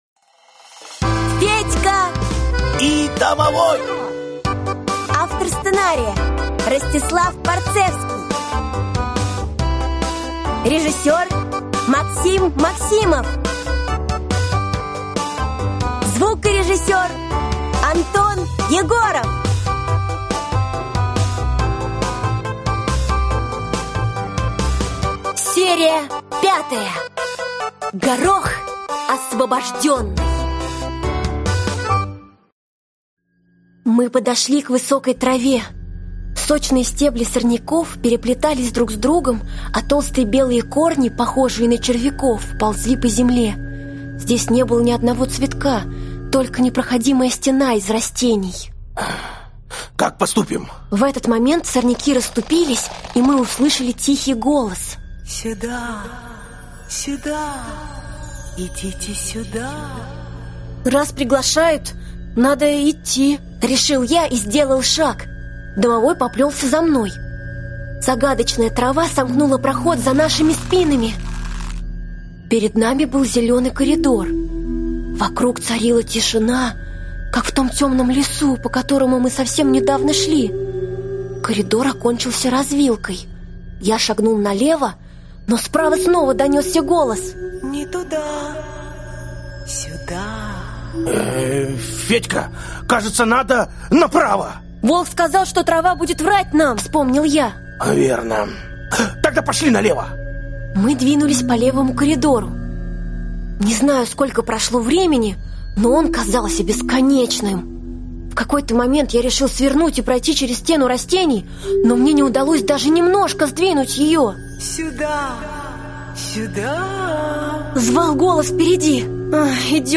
Аудиокнига Федька и домовой. Серия 5 | Библиотека аудиокниг